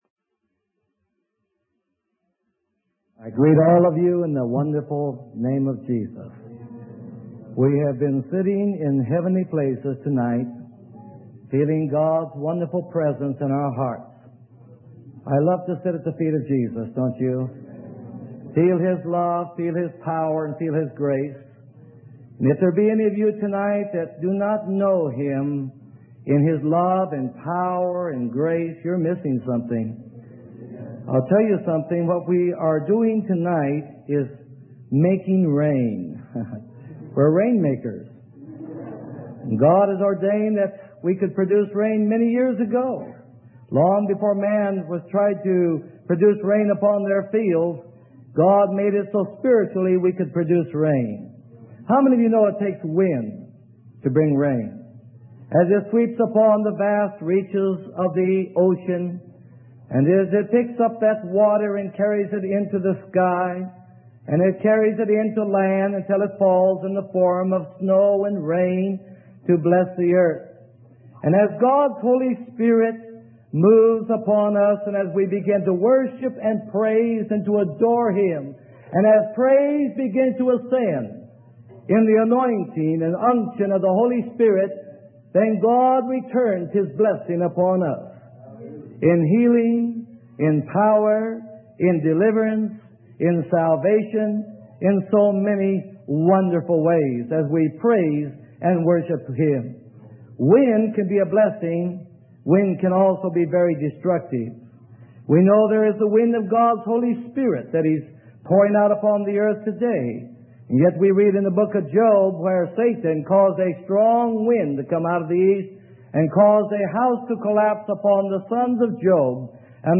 Sermon: Confessing God's Word - Freely Given Online Library